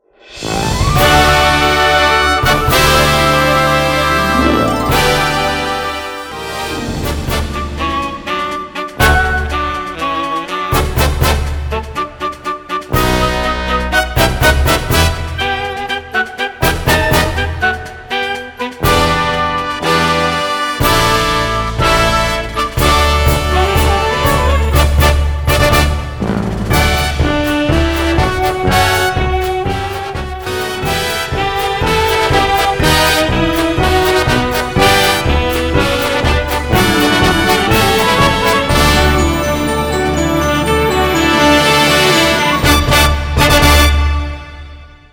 分類 駈足122 時間 4分14秒
編成内容 大太鼓、中太鼓、小太鼓、シンバル、トリオ 作成No ２7９